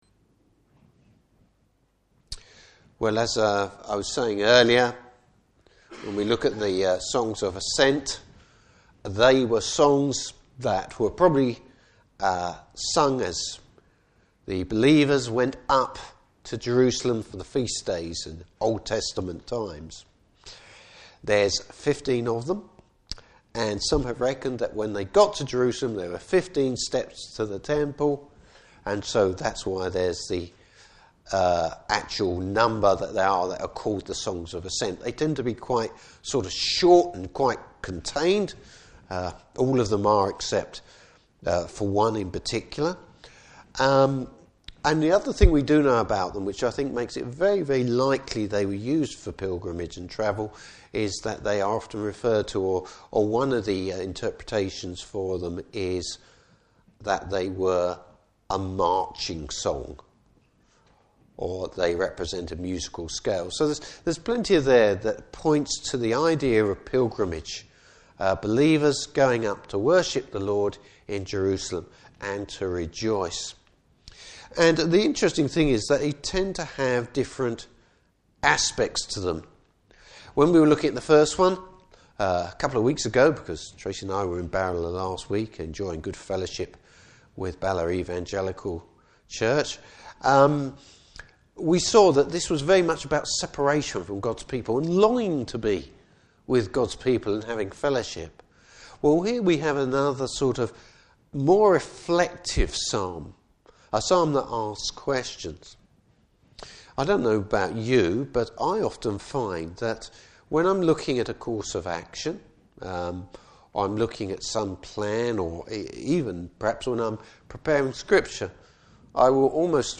Service Type: Evening Service Having a focus on God.